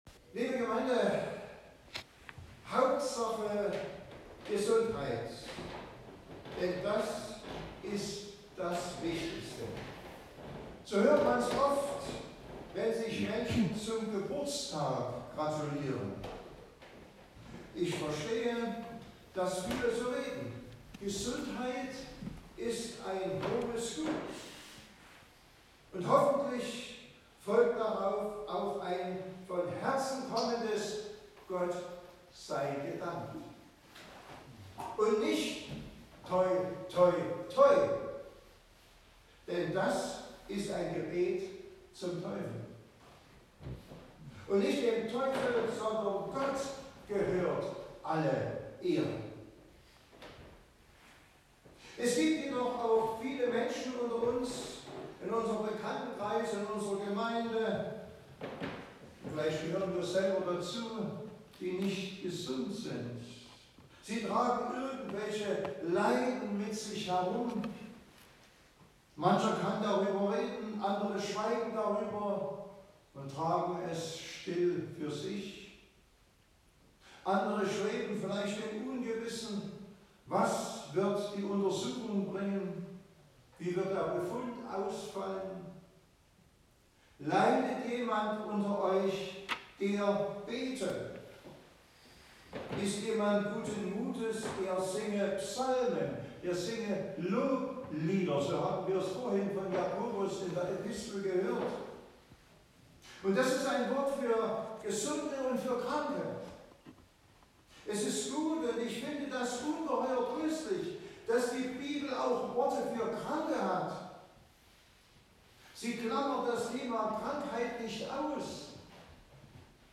Passage: Jakobus 5; 13-16 Gottesdienstart: Predigtgottesdienst « Das Leben des Gläubigen ist die Auswirkung des in ihm lebenden Christus.